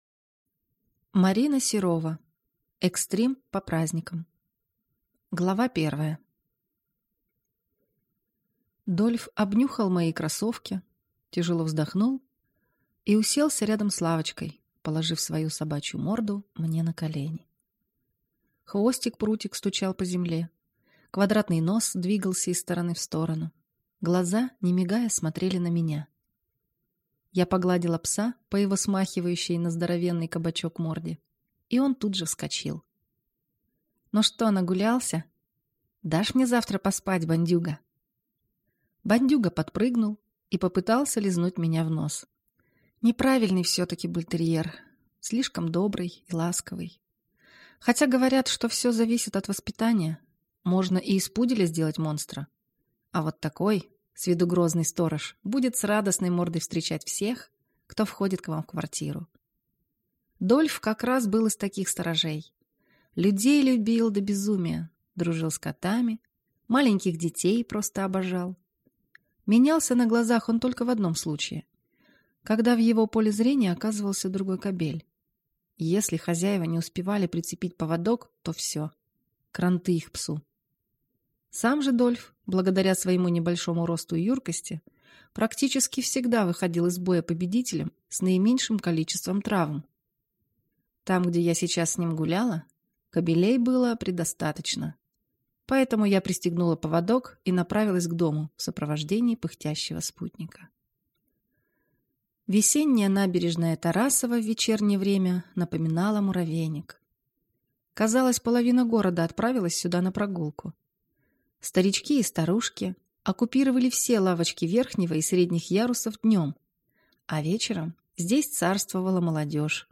Аудиокнига Экстрим по праздникам | Библиотека аудиокниг